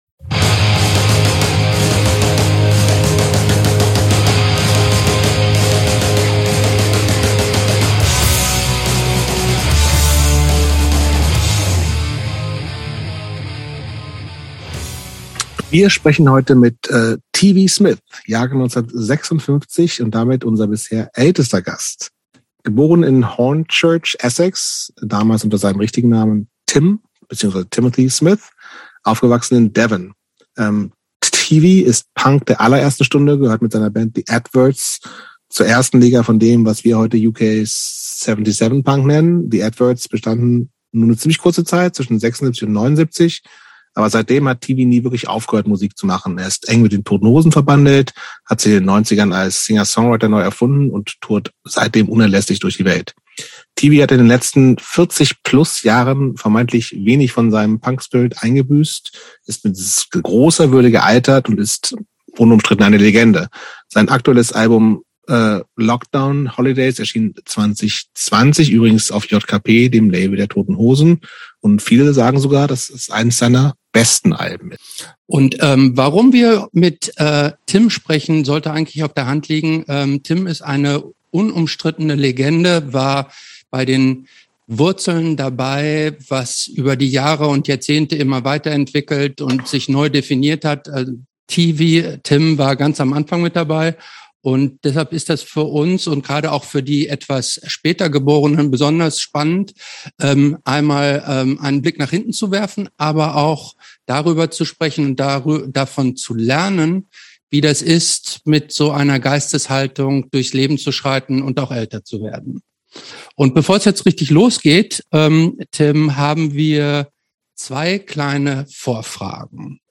Im Gespräch mit TV Smith.